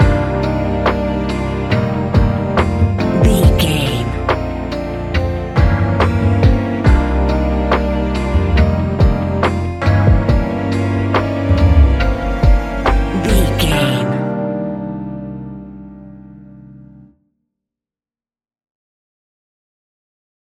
Ionian/Major
laid back
Lounge
sparse
new age
chilled electronica
ambient
atmospheric
morphing
instrumentals